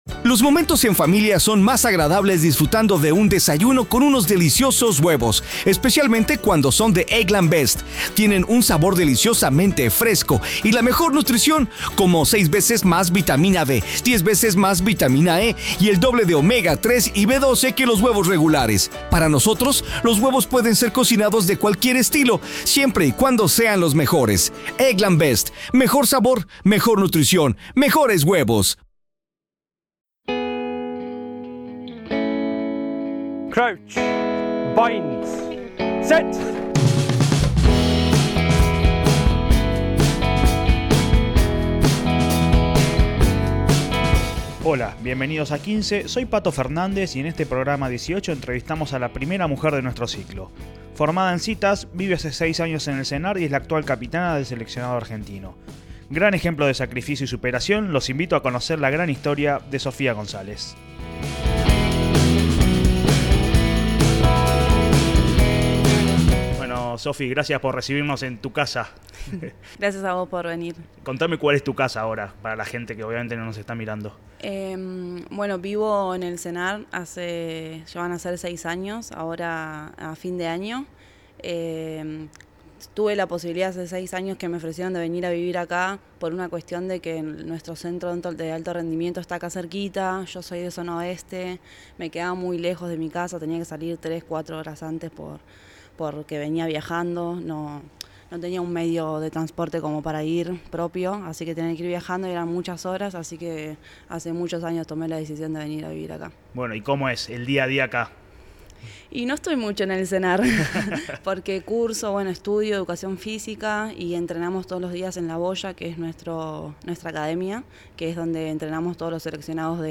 ¡Charlas de rugby con protagonistas!